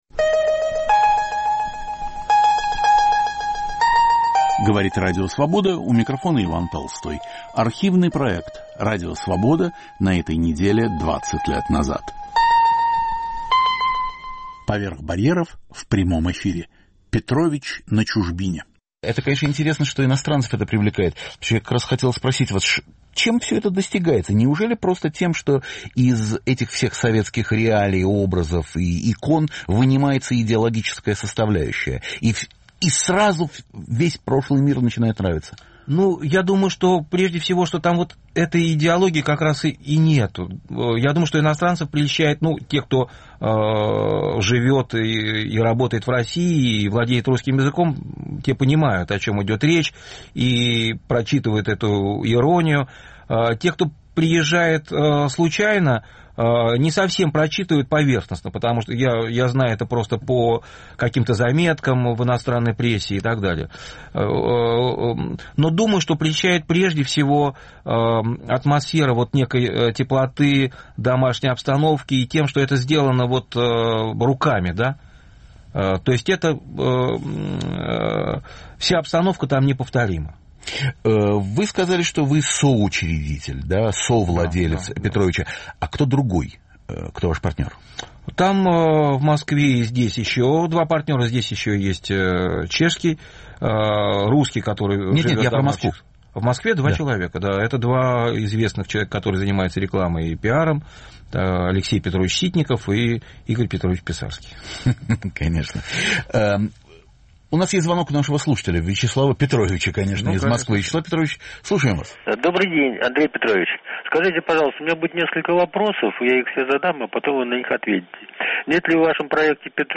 "Поверх барьеров" в прямом эфире. "Петрович" на чужбине
Разговор Ивана Толстого с художником Андреем Бильжо, автором проекта "Петрович".